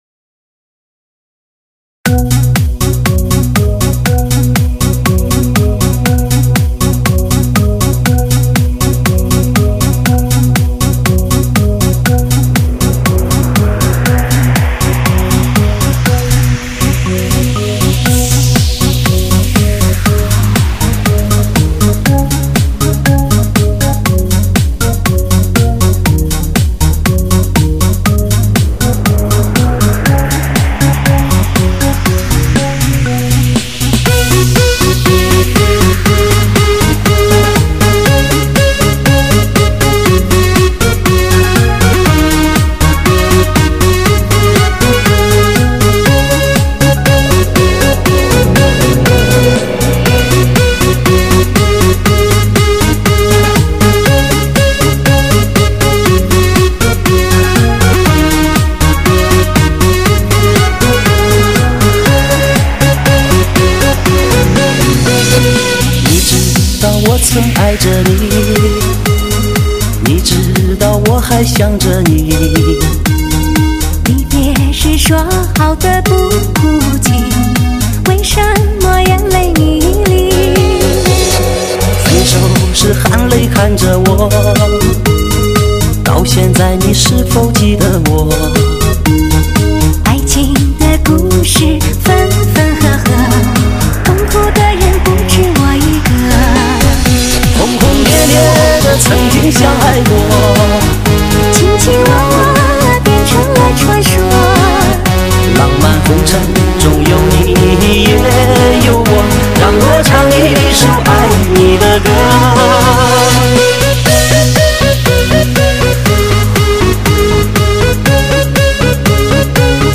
最新最热中文慢摇大碟/给你最舒服的感觉/全国嗨场流行热播中